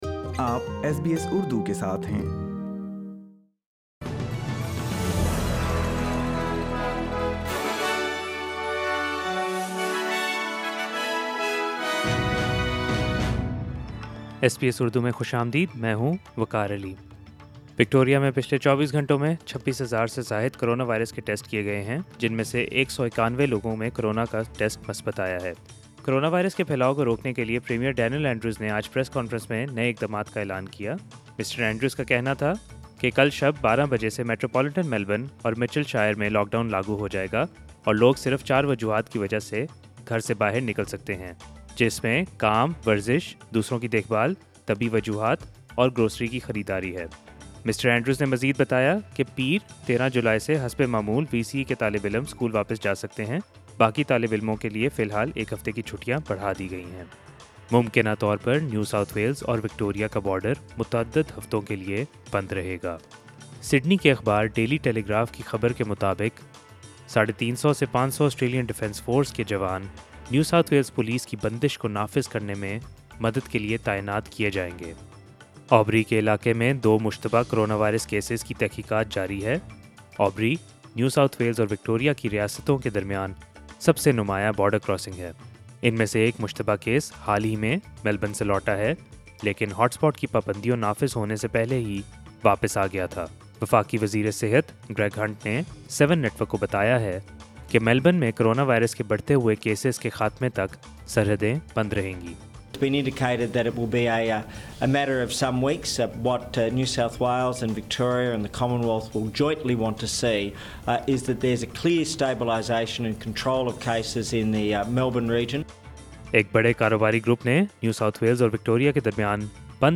sbs_urdu_news_7th_july_2020.mp3